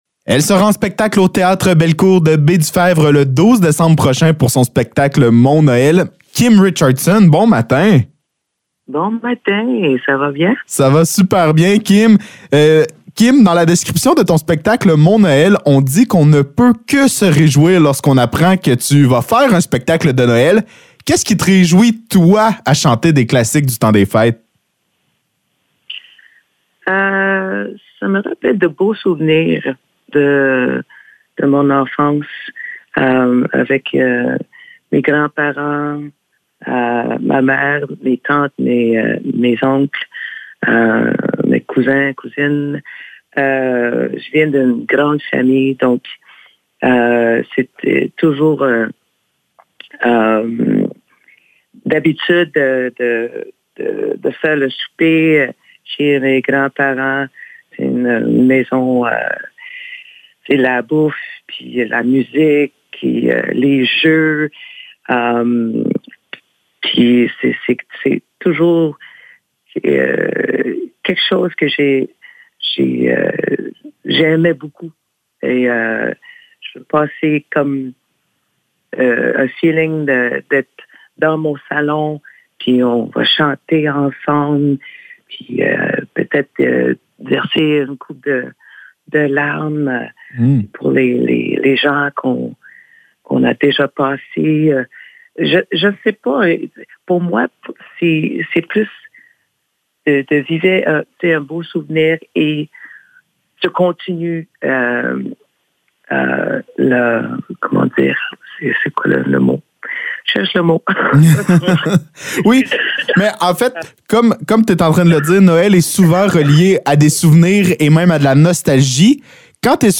Entrevue avec Kim Richardson
Entrevue avec Kim Richardson concernant son spectacle Mon Noël au Théâtre Belcourt de Baie-du-febvre. Elle sera d’ailleurs de retour à Baie-du-febvre au mois d’avril pour le spectacle ELLES avec Lulu Hughes et Luce Dufault.